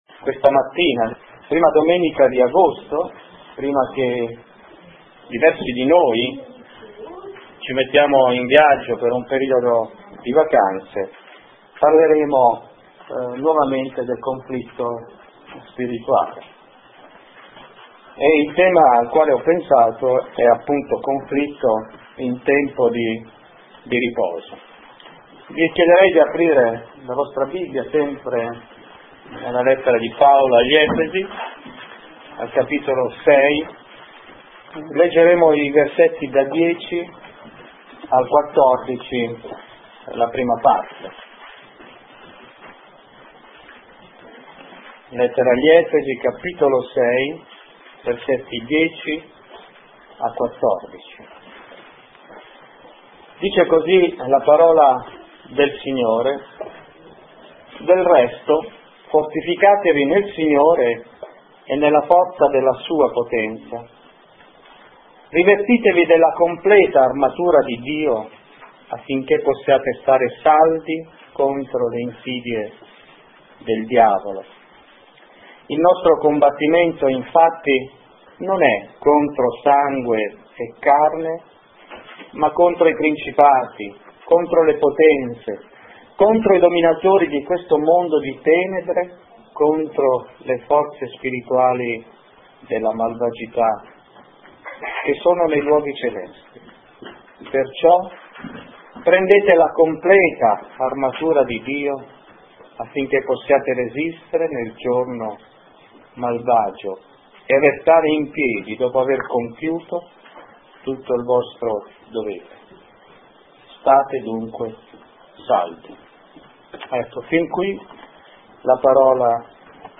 Predicatori Conflitto in tempo di riposo Predicazione sullo stesso tema presso la chiesa Sola Grazia di Caltanissetta.